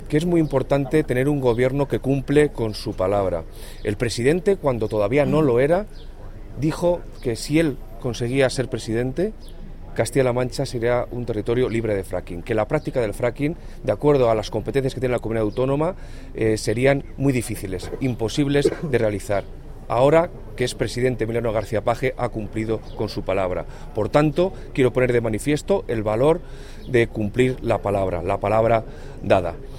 El delegado de la Junta en Guadalajara, Alberto Rojo, habla del cumplimiento de la palabra dada por el presidente con la eliminación del fracking de la región.